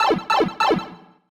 Pipe Travel sound effect from Super Mario 3D World.
SM3DW_Pipe_Travel.oga.mp3